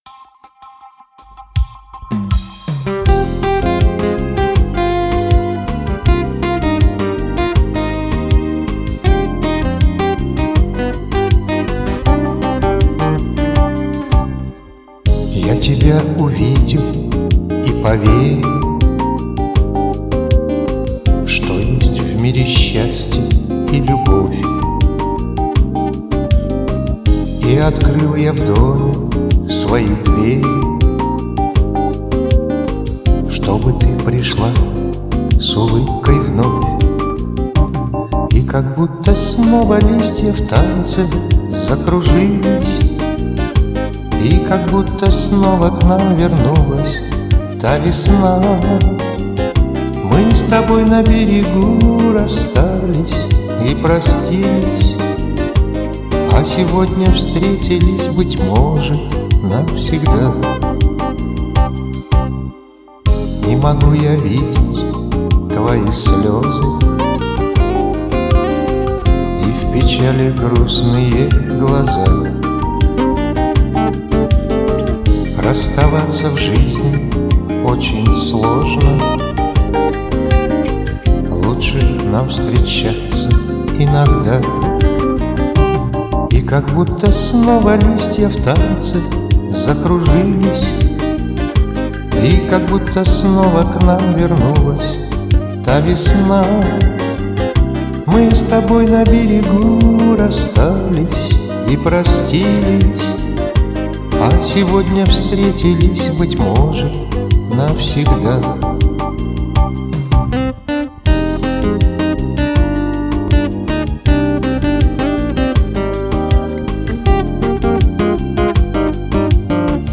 приятно звучит, тепло
это "свистящие" звуки в записи
его исполнение как-то приятнее и доверительнее